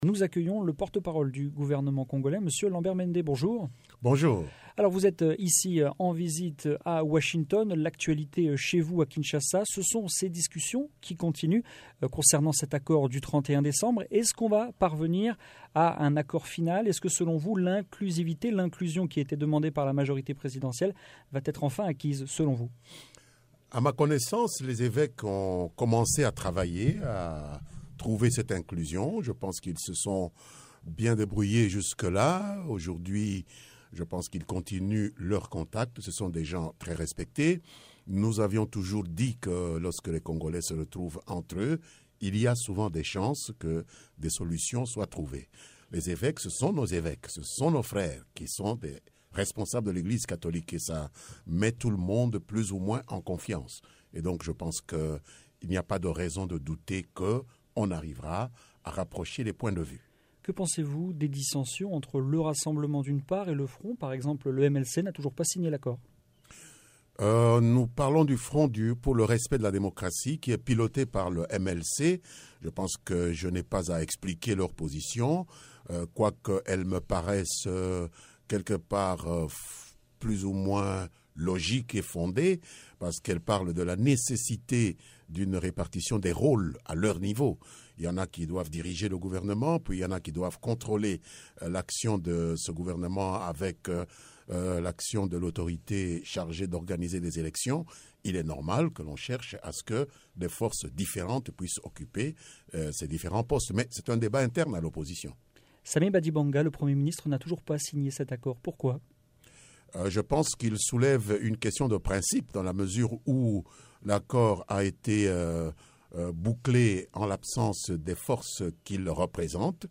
De passage à Washington, le porte-parole du gouvernement congolais répond aux questions de VOA Afrique : accord politique, sanctions américaines et organisation des élection, Lambert Mende fait le point. Entretien.